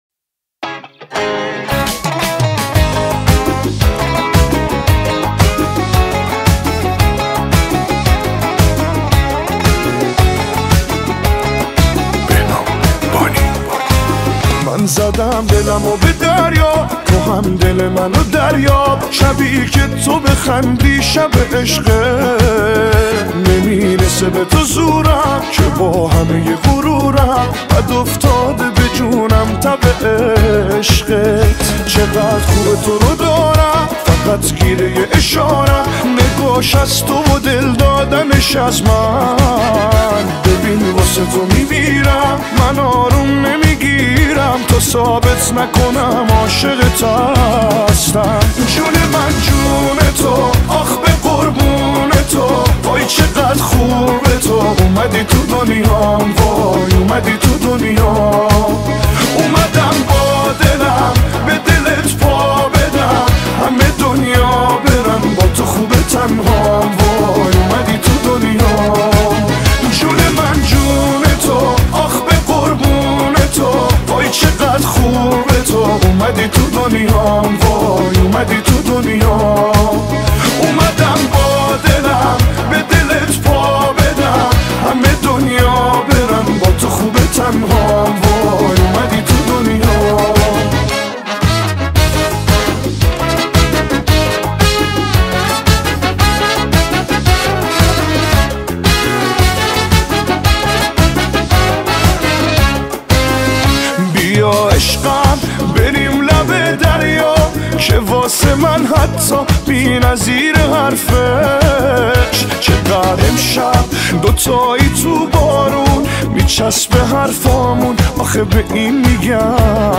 شاد
شاد فارسی